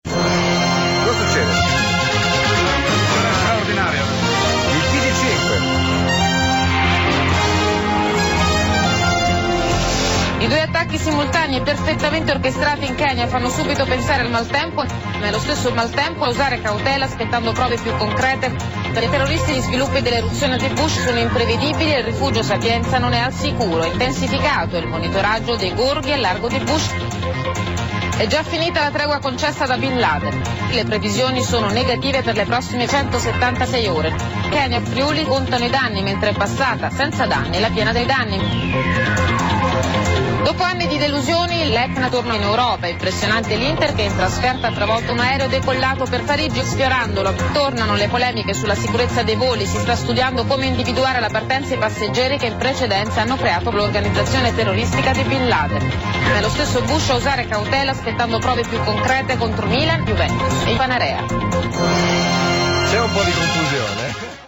Alcuni titoli dei Tg rivisti dagli Elio e le storie tese all'interno del programma "Cordialmente" in onda su Radio Deejay.